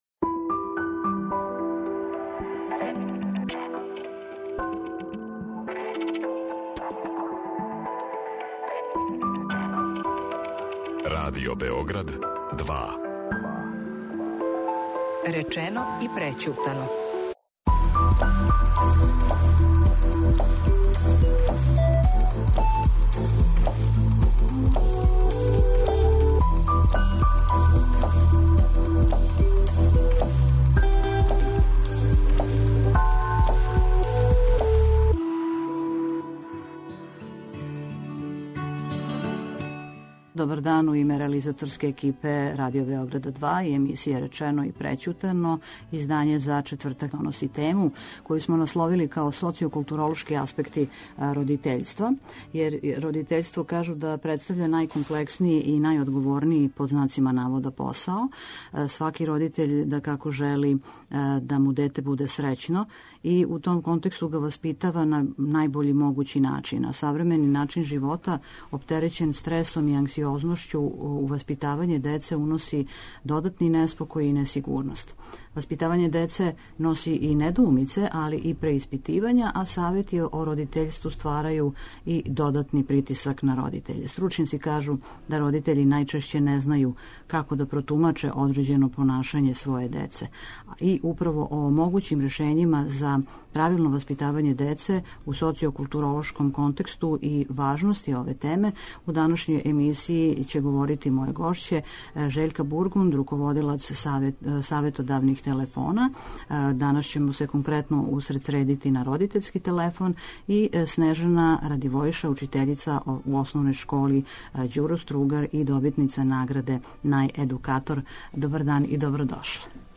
У данашњој репризној емисији подсећамо на родитељство као најкомплекснији и најодговорнији „посао'.